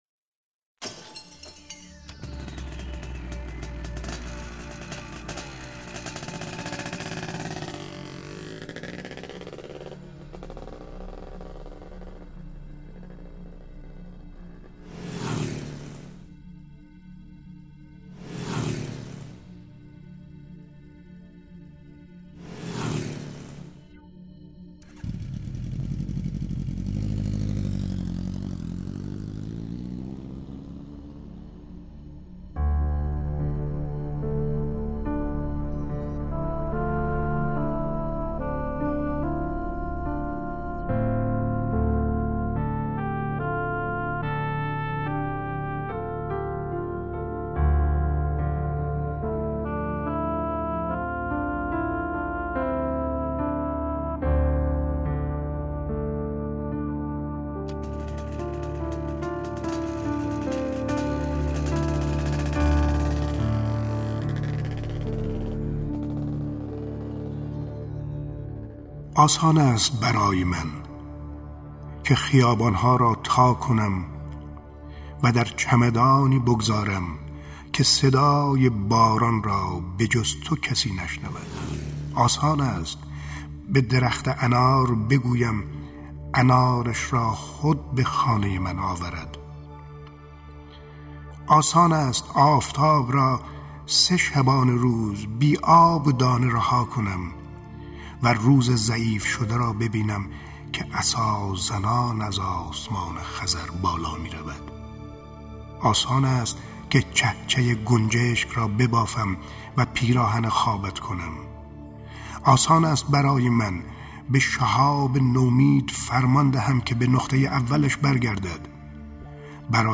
دانلود دکلمه آسان است برای من با صدای شمس لنگرودی با متن دکلمه